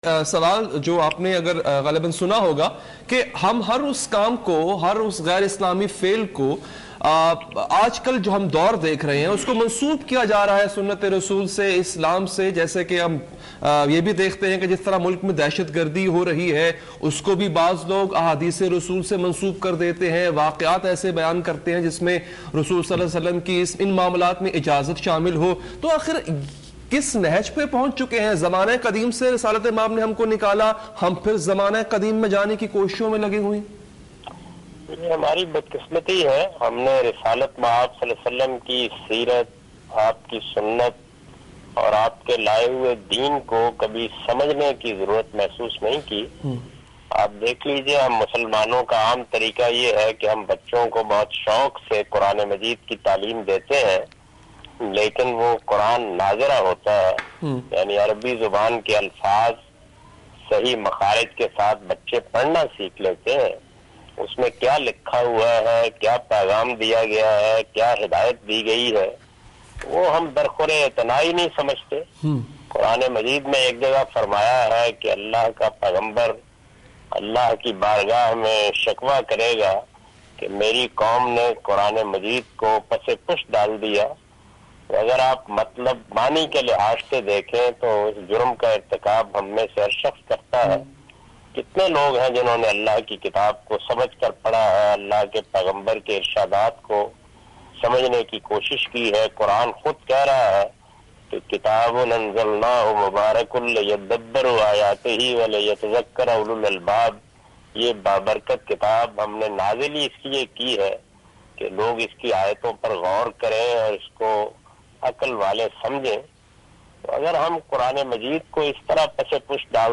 Category: TV Programs / Questions_Answers /